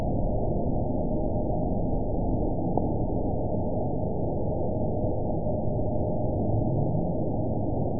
event 914207 date 04/30/22 time 21:10:06 GMT (3 years ago) score 8.04 location TSS-AB01 detected by nrw target species NRW annotations +NRW Spectrogram: Frequency (kHz) vs. Time (s) audio not available .wav